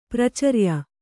♪ pracarya